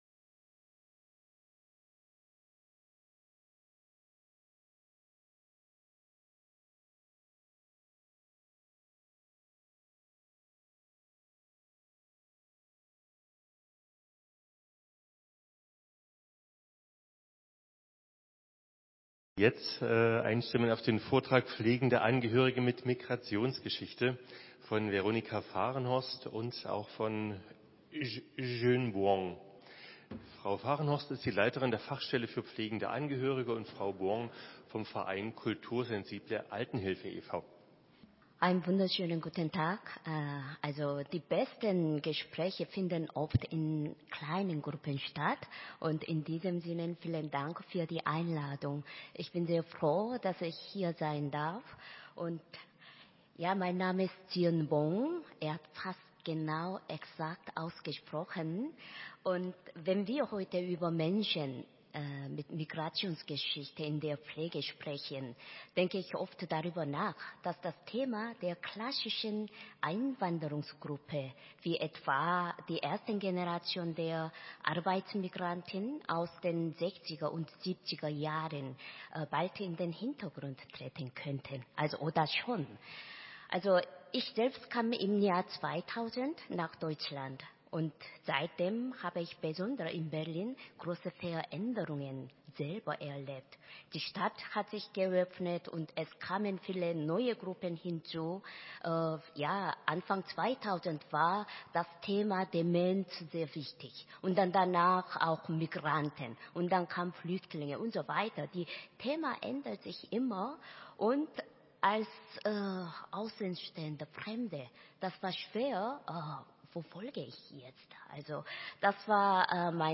Vortrag 1: Herausforderungen und Chancen der Pflege im Bezirk Tempelhof-Schöneberg